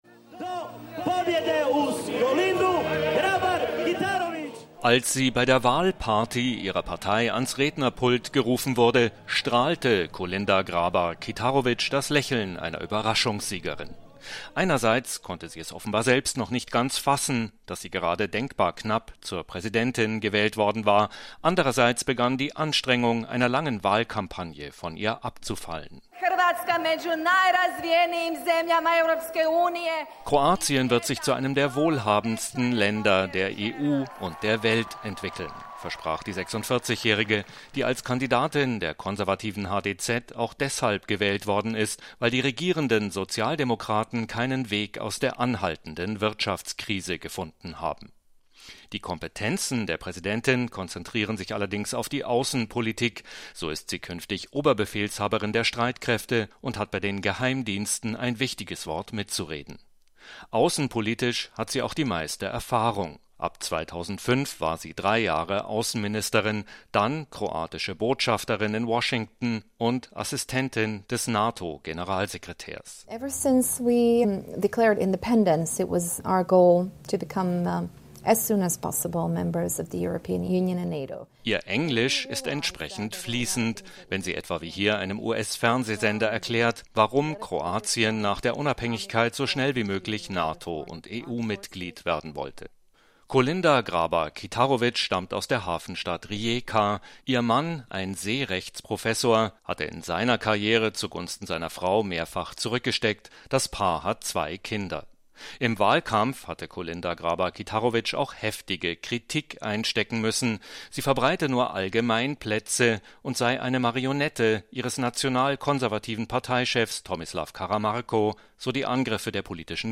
berichtet aus zagreb